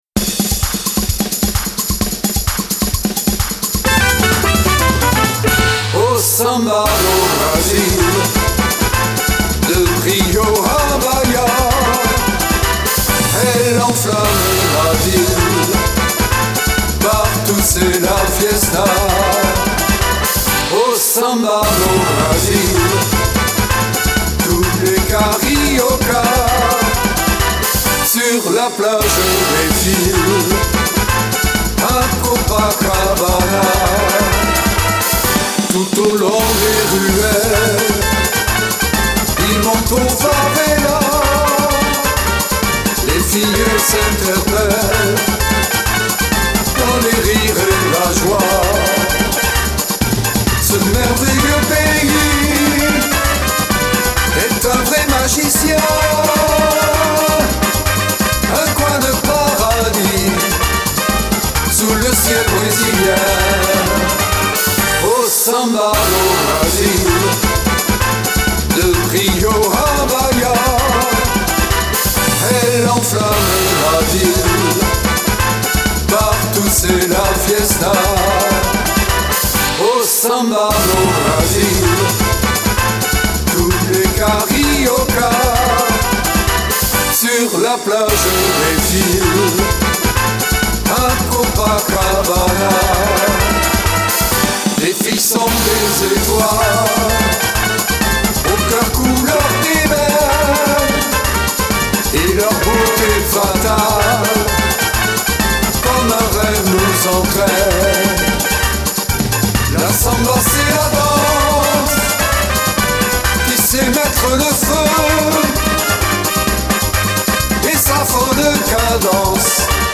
(Batucada)
version chantée